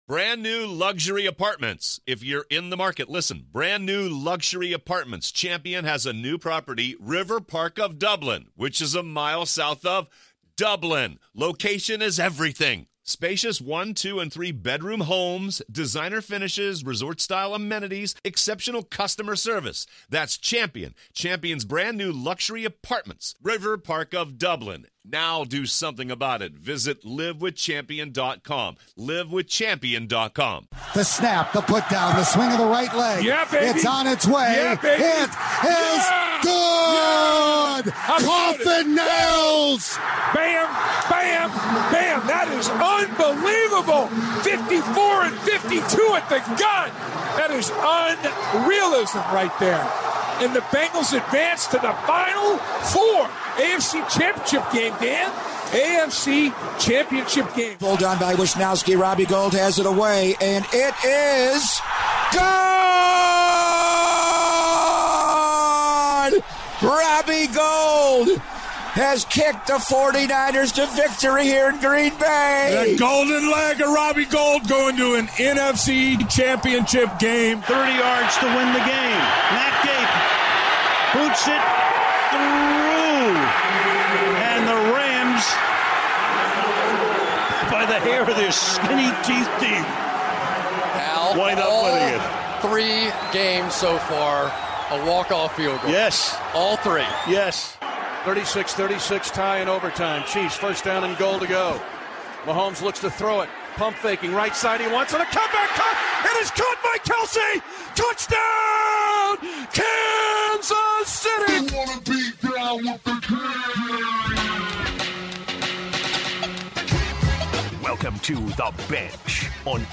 Callers also share what they learned over the weekend.